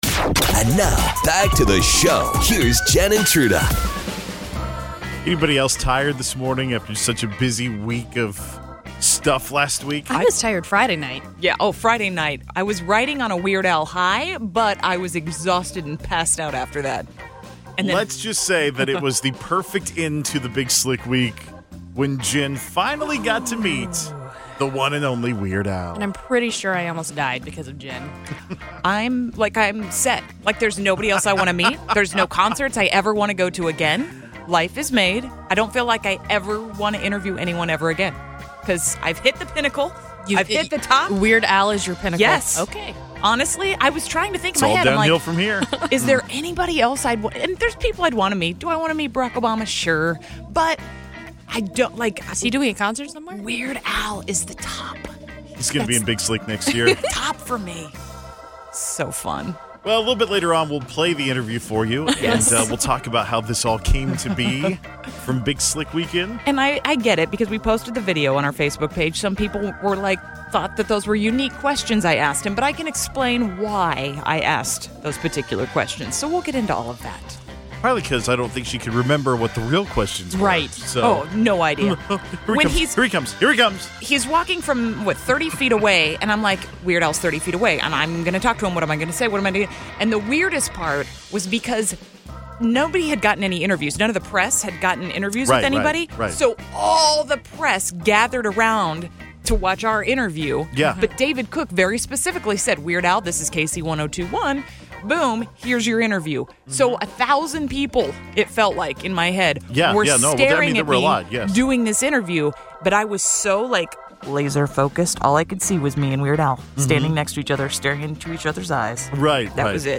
Hear the interview and how it happened right here! We play the Whisper Challenge for Adam Lambert tickets.